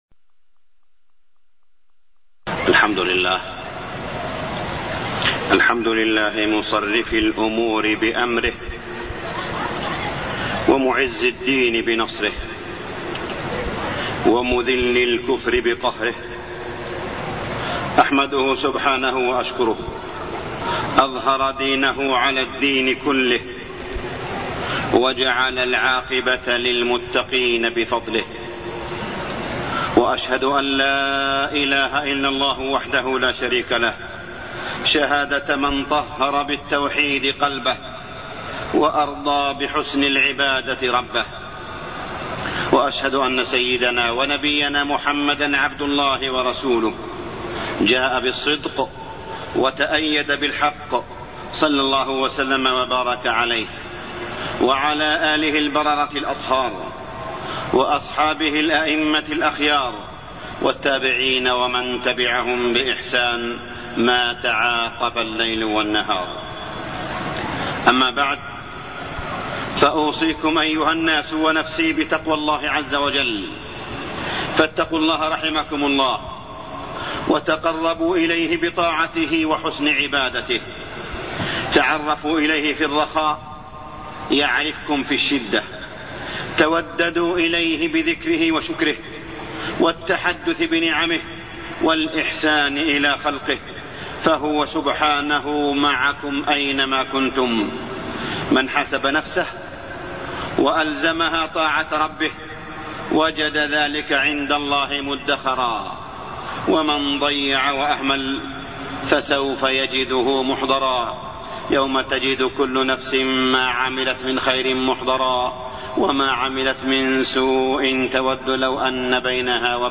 تاريخ النشر ٥ رمضان ١٤٢١ هـ المكان: المسجد الحرام الشيخ: معالي الشيخ أ.د. صالح بن عبدالله بن حميد معالي الشيخ أ.د. صالح بن عبدالله بن حميد شهر القرآن والفرقان